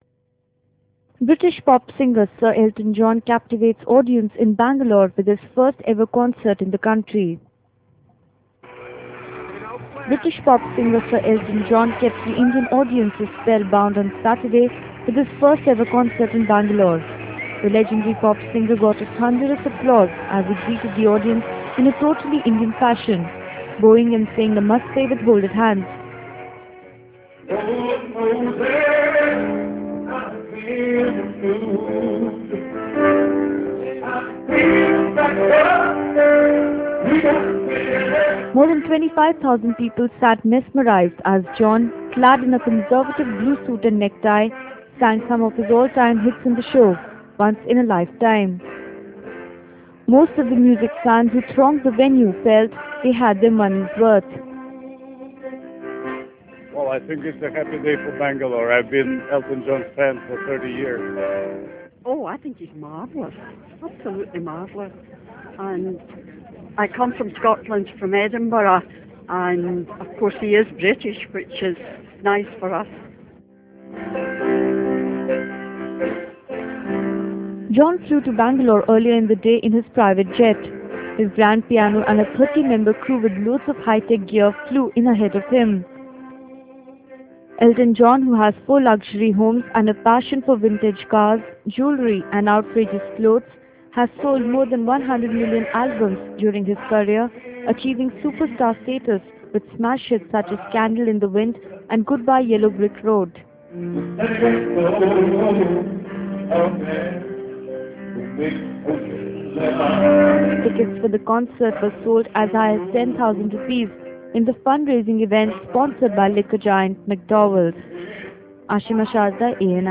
during a performance at a show in Bangalore